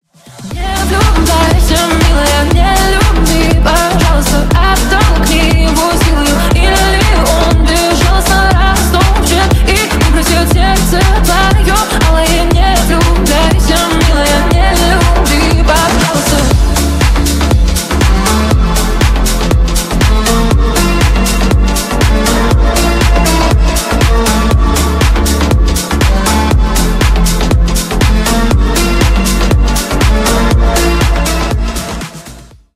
бесплатный рингтон в виде самого яркого фрагмента из песни
Ремикс # Поп Музыка